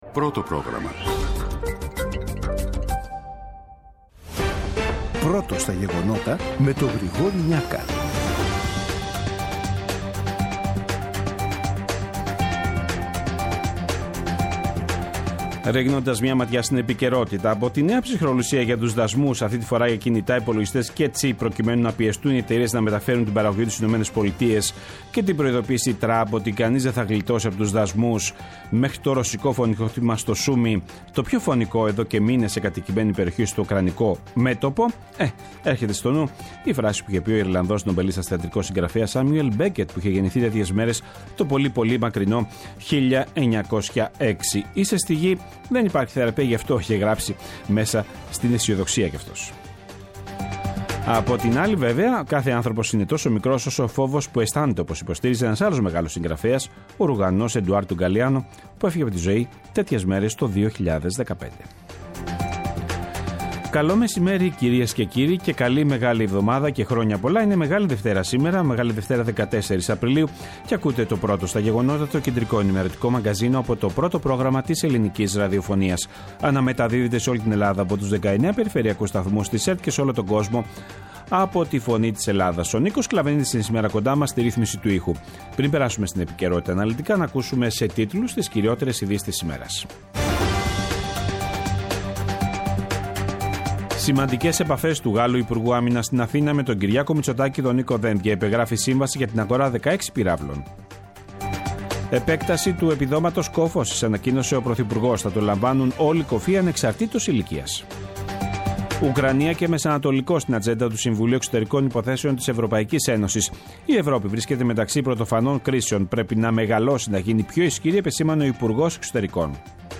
Το αναλυτικό ενημερωτικό μαγκαζίνο του Α΄ Προγράμματος, από Δευτέρα έως Παρασκευή στις 14:00. Με το μεγαλύτερο δίκτυο ανταποκριτών σε όλη τη χώρα, αναλυτικά ρεπορτάζ και συνεντεύξεις επικαιρότητας. Ψύχραιμη ενημέρωση, έγκυρη και έγκαιρη.